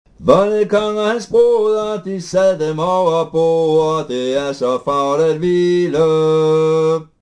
2. Omkvæd:
Balladens grundform + omkvæd (musiksprog: slutvending)